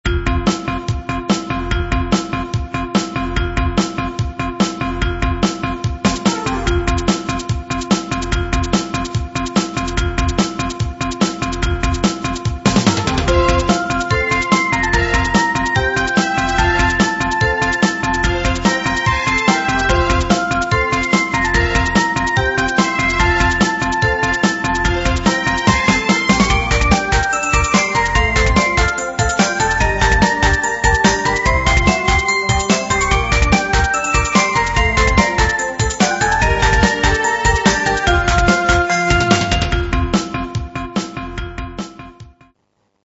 （音質　16kbps〜48kbps　モノラル）